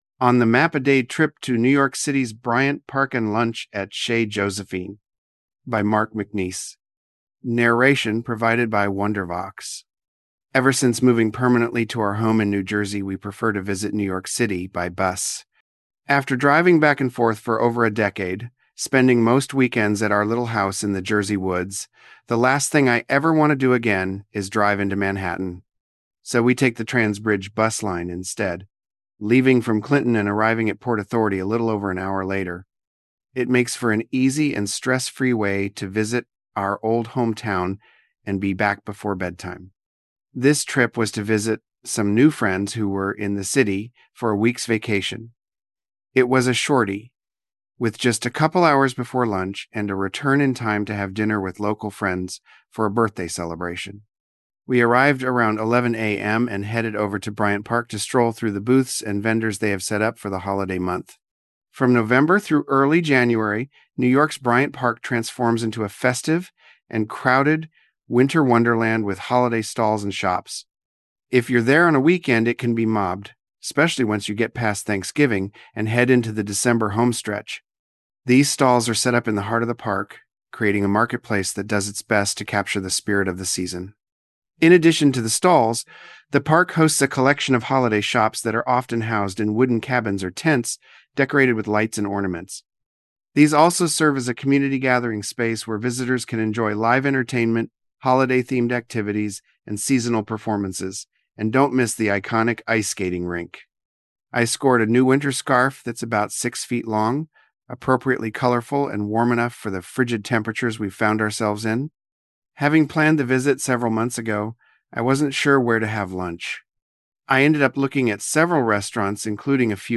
Narration provided by Wondervox.